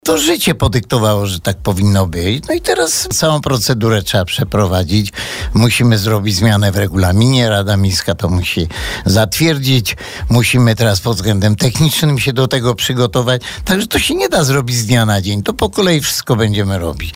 Pytanie to zadaliśmy Antoniemu Szlagorowi podczas niedawnego spotkania na antenie naszego radia. Gospodarz Żywca odrzekł, że darmowy kwadrans będzie, ale nie się tego zrobić od razu.